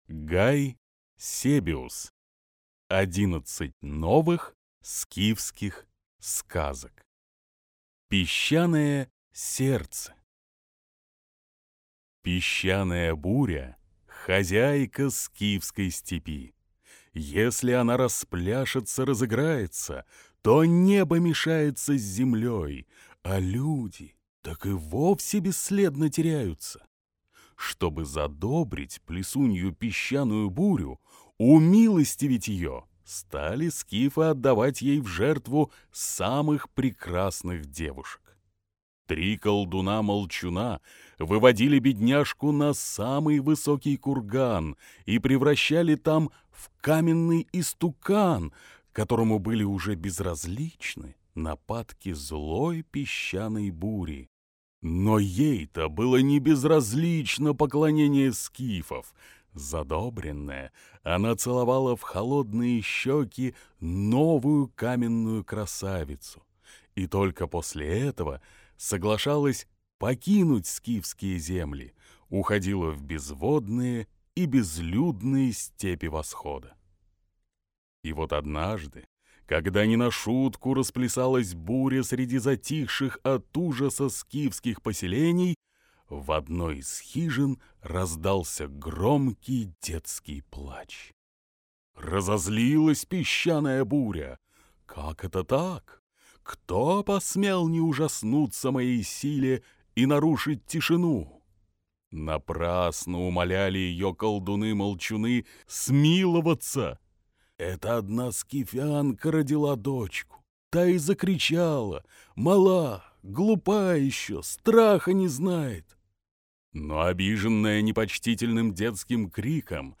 Аудиокнига 11 новых скифских сказок | Библиотека аудиокниг